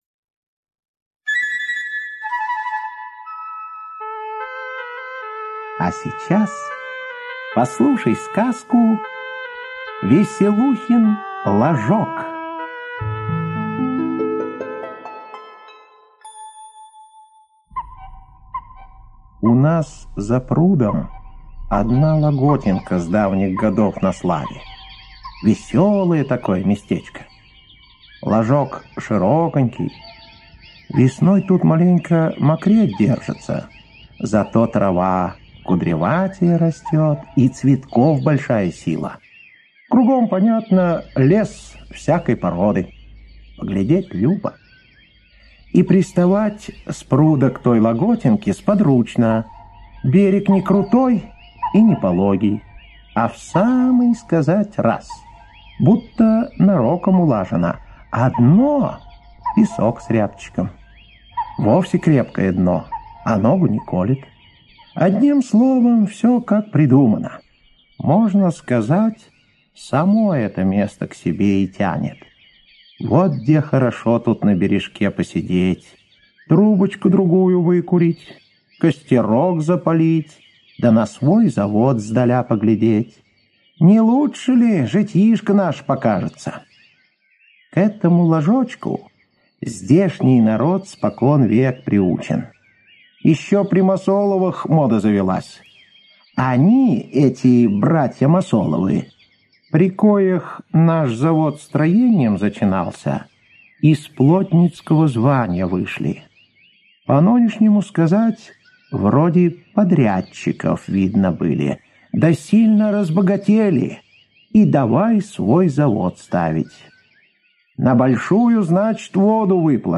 Слушайте Веселухин ложок - аудиосказка Бажова П. Сказ про один знатный ложок, на который люди плавали на лодках, чтобы погулять.